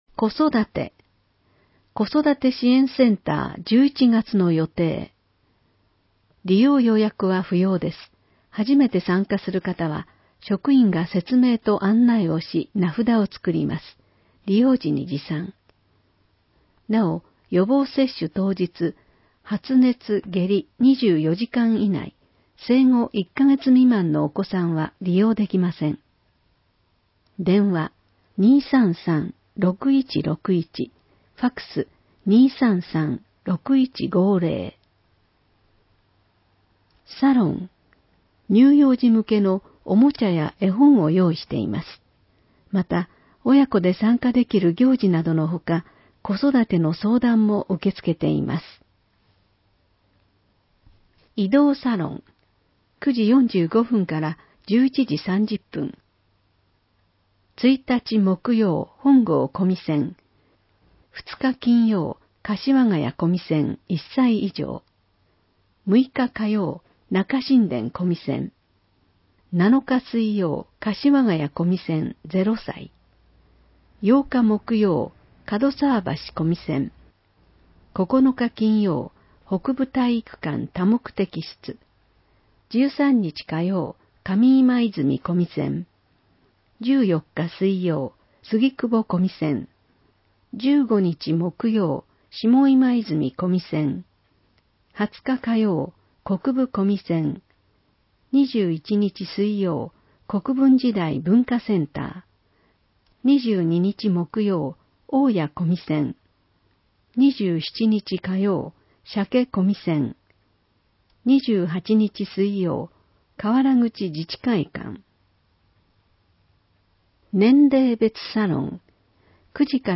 ※音声版は、音声訳ボランティア「矢ぐるまの会」の協力により、同会が視覚障がい者の方のために作成したものを、順次搭載します。